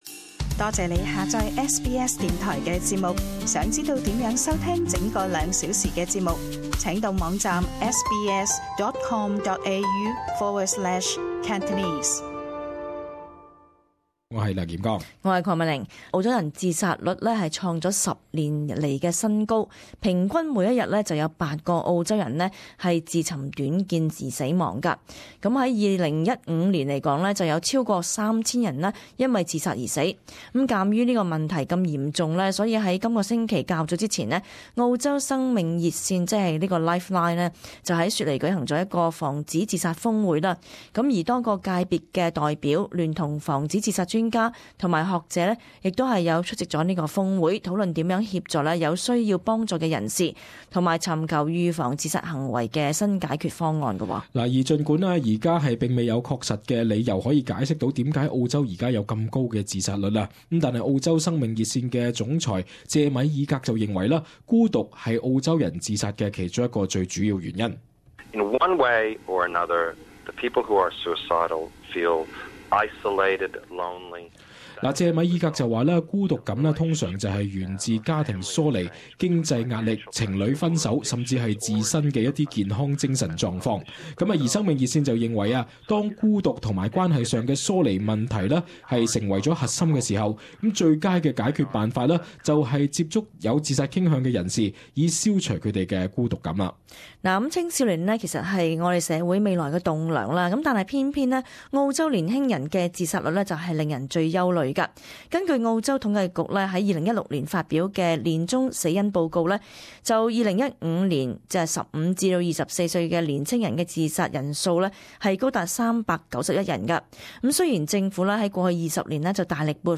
【時事報導】澳洲平均每天有八人自殺